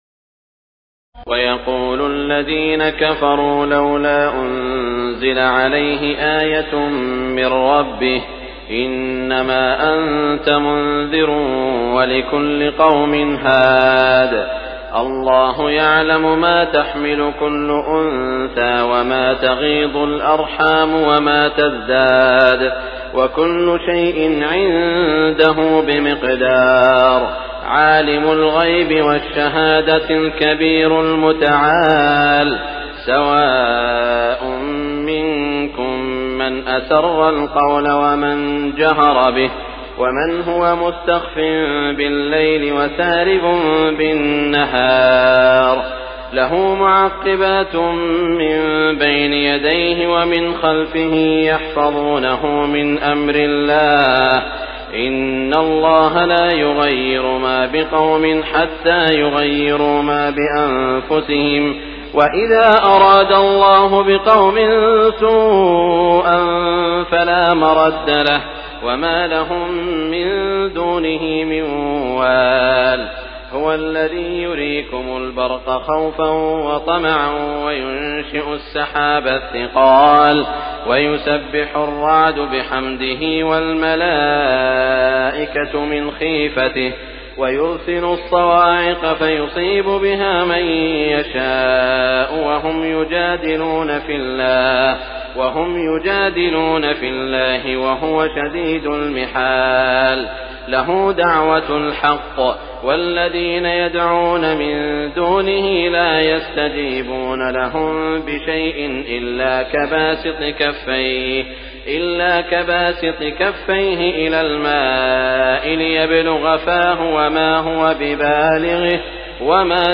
تراويح الليلة الثالثة عشر رمضان 1418هـ من سورتي الرعد (7-43) و إبراهيم (1-41) Taraweeh 13 st night Ramadan 1418H from Surah Ar-Ra'd and Ibrahim > تراويح الحرم المكي عام 1418 🕋 > التراويح - تلاوات الحرمين